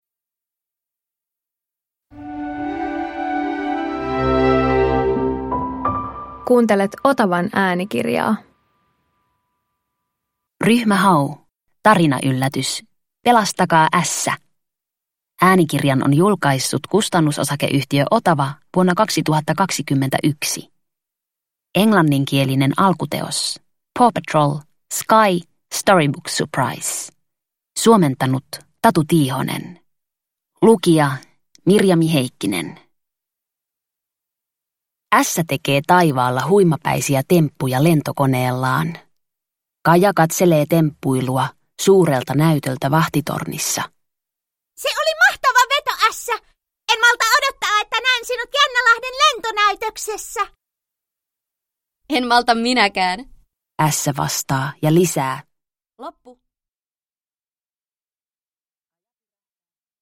Ryhmä Hau - Pelastakaa Ässä – Ljudbok – Laddas ner